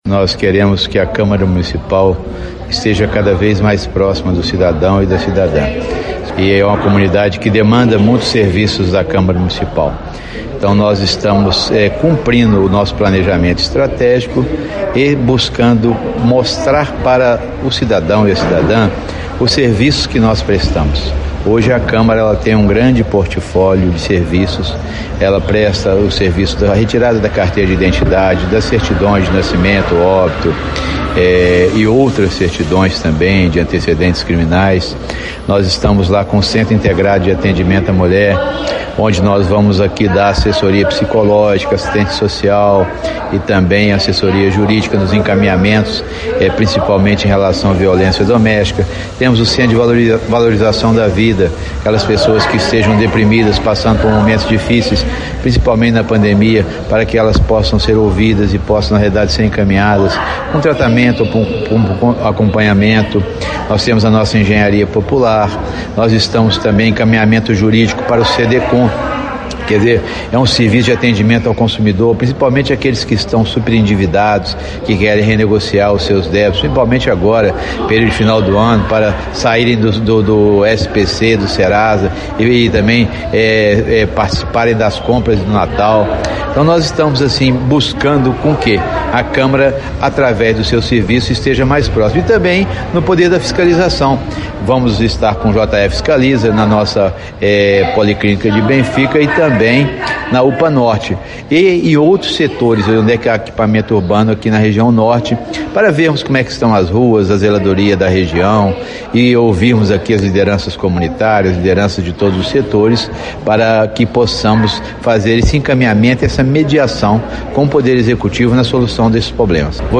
O presidente da Câmara, vereador Juracy Scheffer (PT) fala sobre os serviços oferecidos.